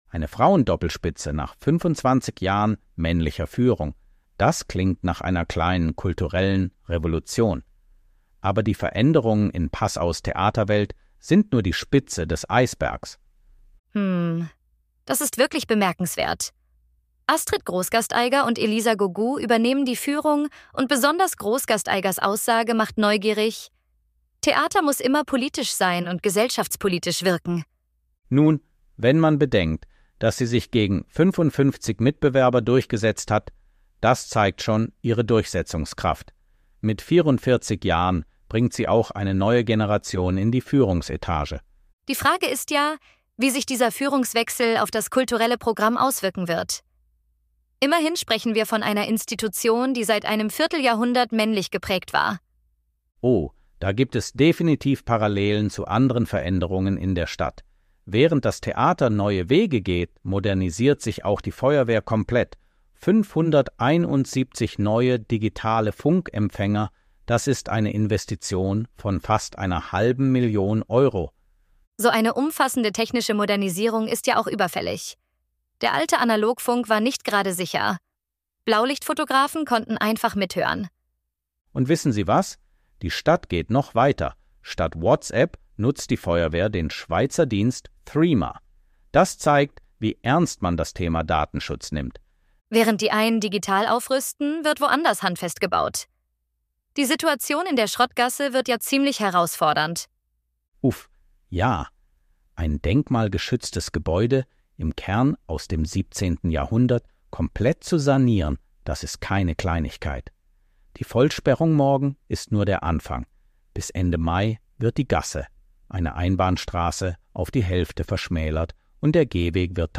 Unser Nachrichtenticker der zweiten Märzwoche als KI-Kost: „Dialog